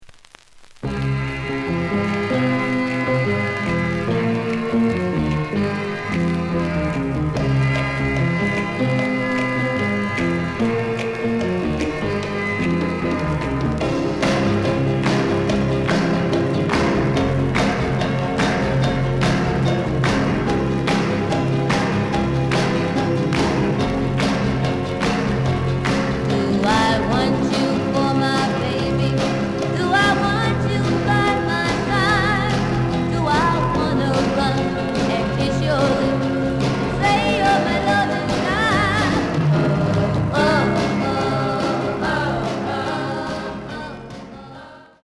The audio sample is recorded from the actual item.
●Genre: Rock / Pop
Edge warp.